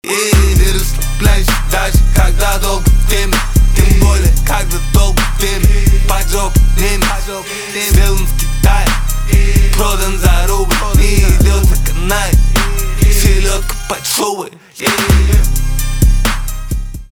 русский рэп
битовые , басы , жесткие , хип-хоп